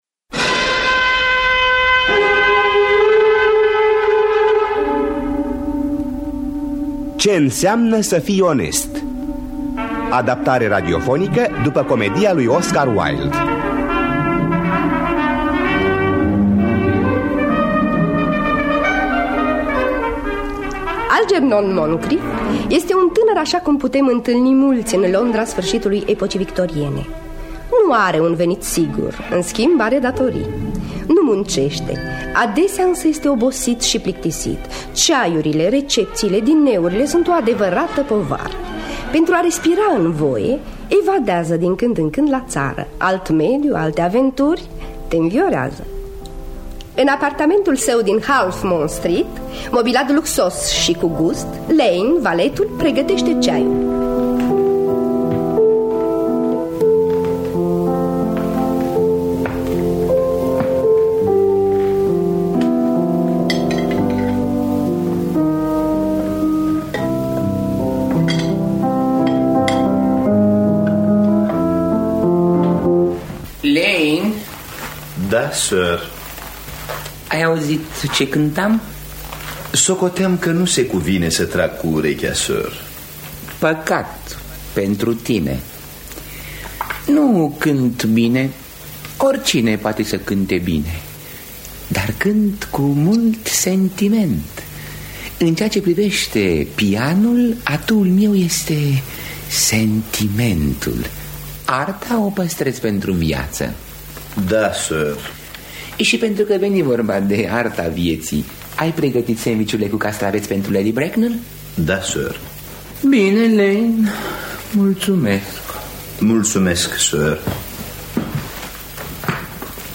Traducerea și adaptarea radiofonică